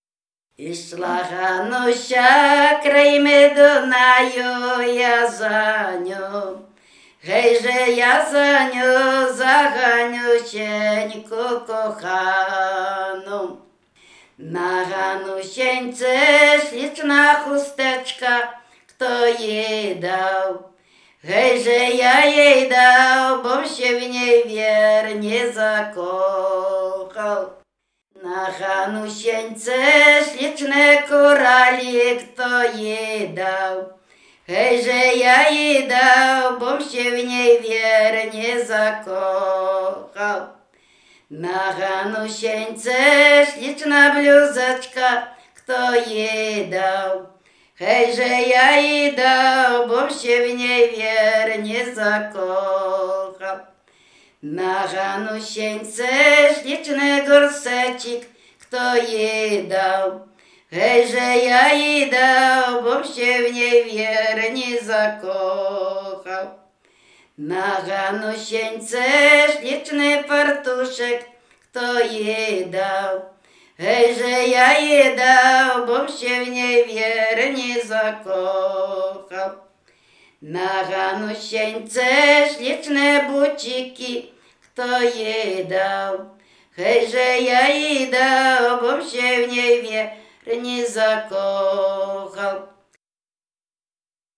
W wymowie Ł wymawiane jako przedniojęzykowo-zębowe;
Dunajowa